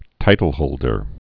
(tītl-hōldər)